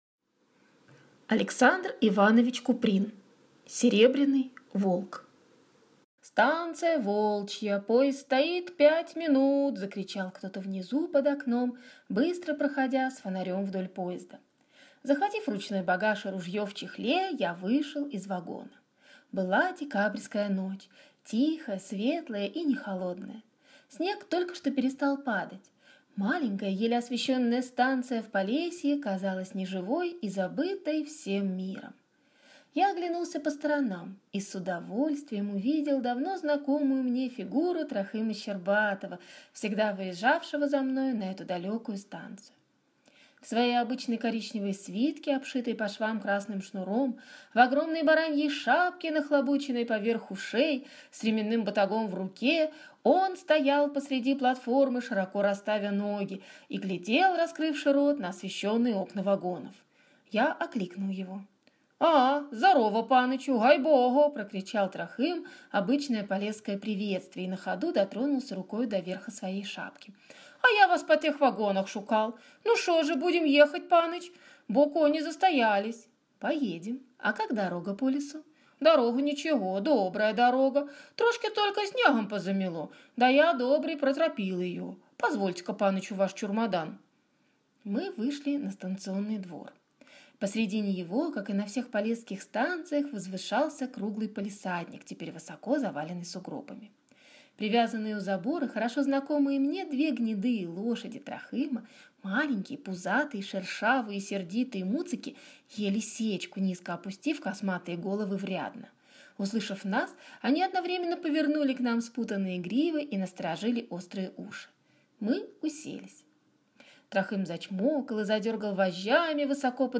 Аудиокнига Серебряный волк | Библиотека аудиокниг